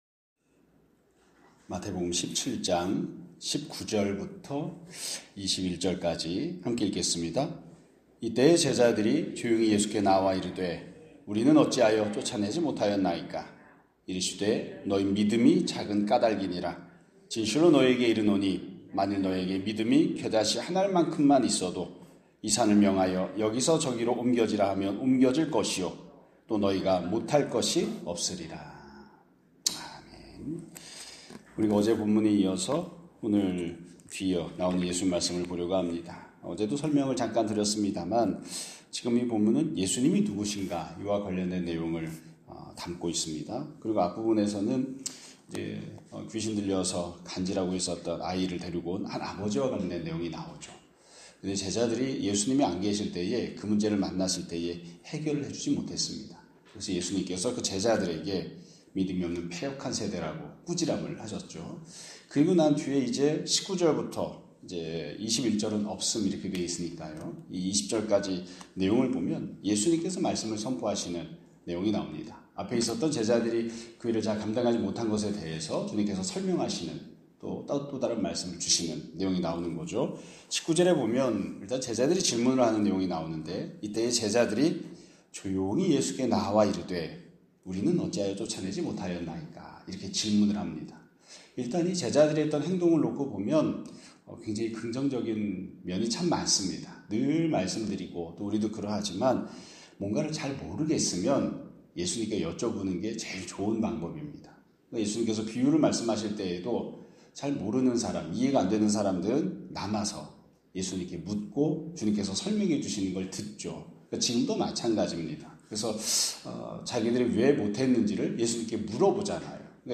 2025년 11월 27일 (목요일) <아침예배> 설교입니다.